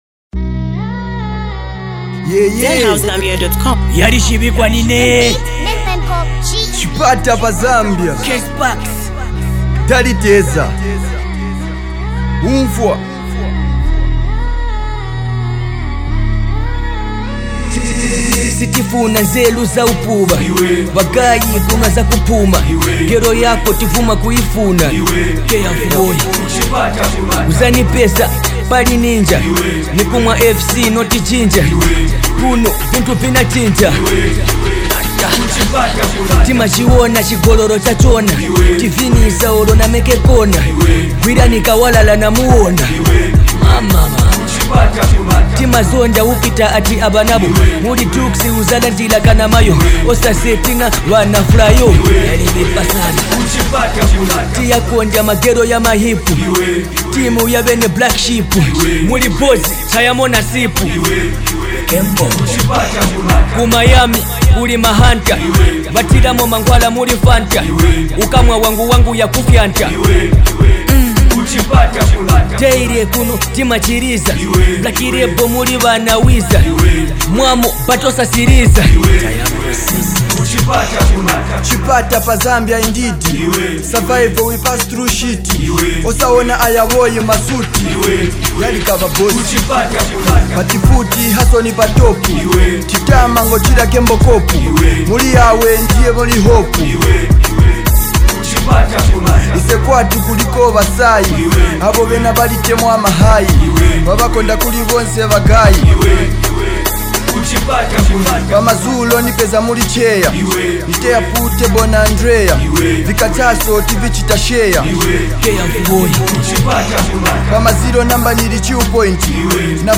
A feel-good track that honors Chipata’s pride and progress!